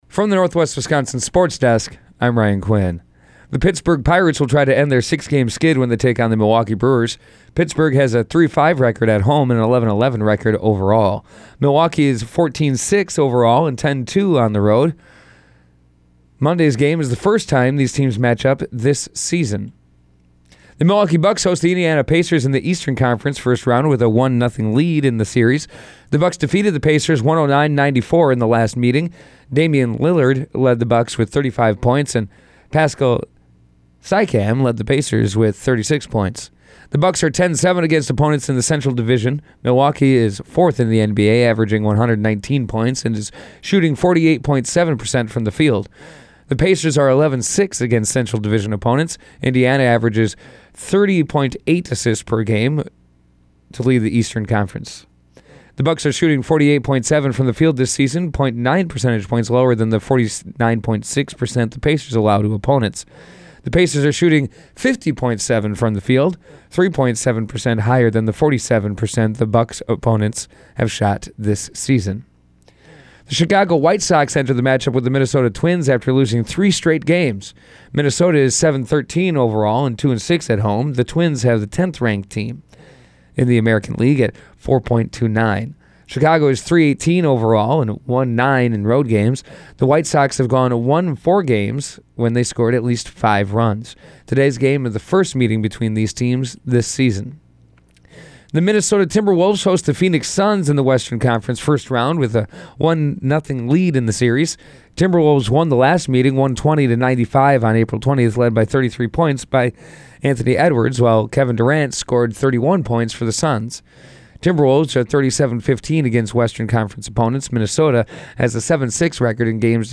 Today’s sportscast from the Northwest Wisconsin Sports Desk.